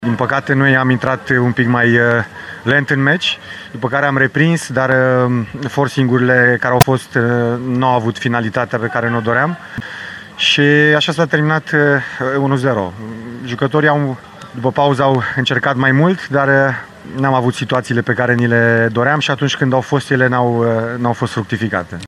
Declarațiile ”la cald” din tabăra Bătrânei Doamne au scos în evidență startul ratat de întâlnire, care a fost până la urmă decisiv în stabilirea învingătoarei.